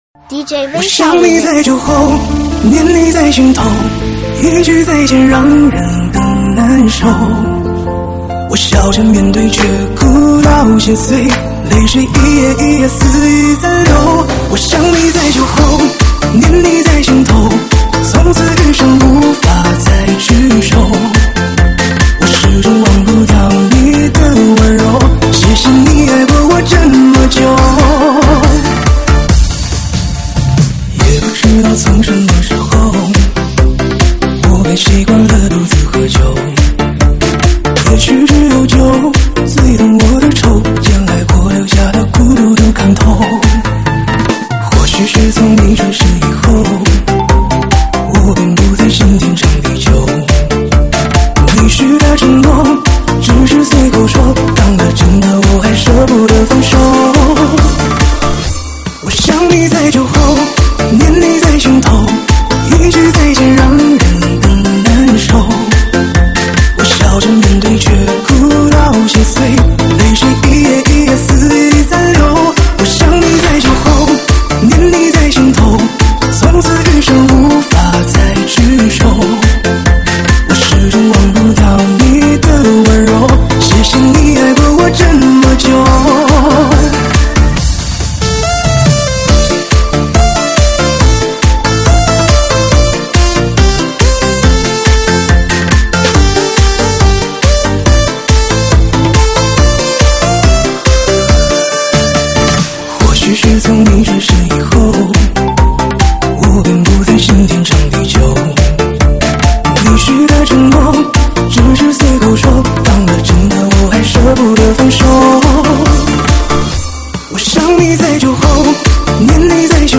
车载大碟